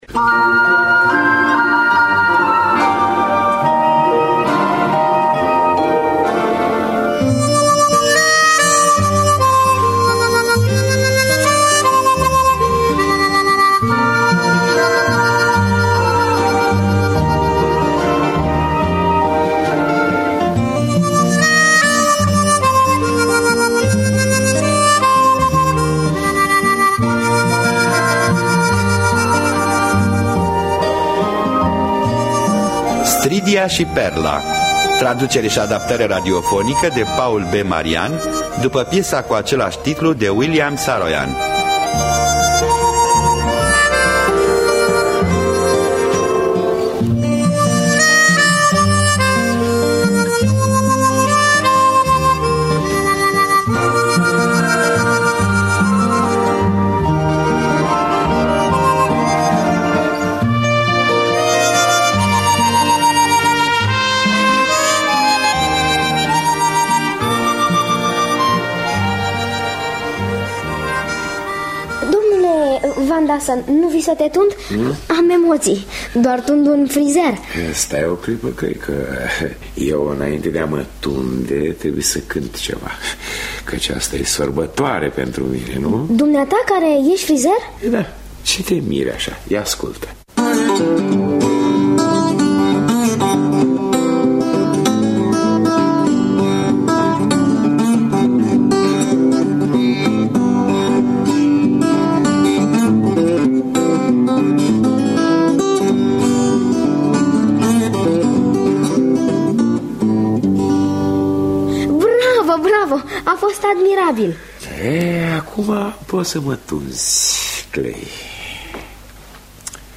Traducerea și adaptarea radiofonică